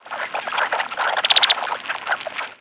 Brown-headed Cowbird ~4.5 kHz ~20 mS
Brown-headed Cowbird This species' rattle is typically longer than any longspur's rattle and it usually increases in loudness and pitch, unlike longspur rattles.